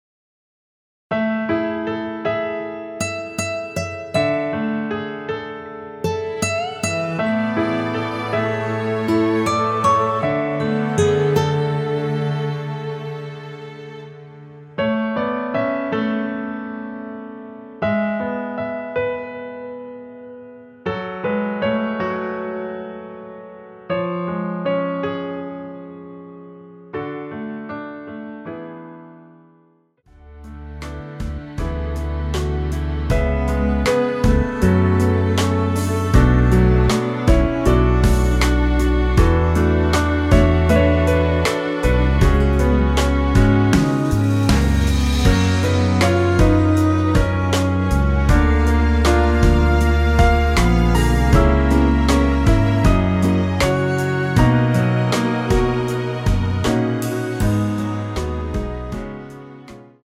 원키에서(+1)올린 MR입니다.
◈ 곡명 옆 (-1)은 반음 내림, (+1)은 반음 올림 입니다.
앞부분30초, 뒷부분30초씩 편집해서 올려 드리고 있습니다.